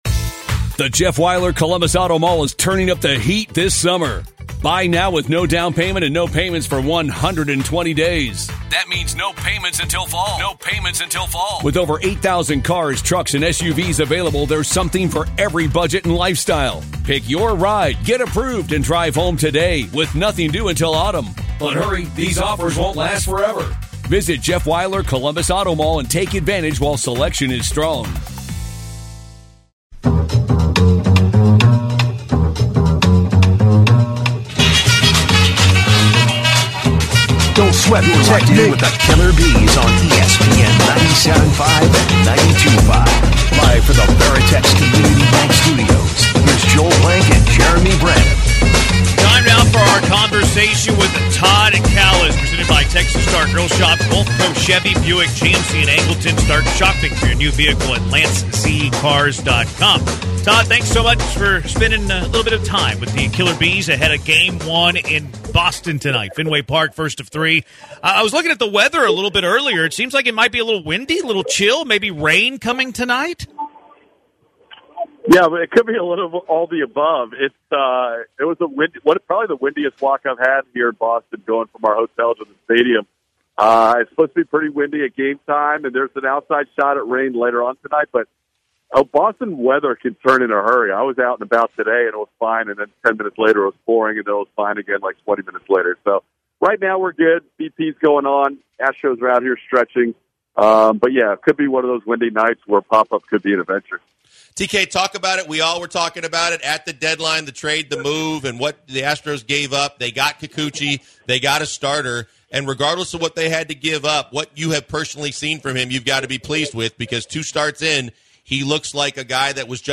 live from Fenway Park to talk about the Astros-Red Sox series this weekend, Verlander's nearing return, and more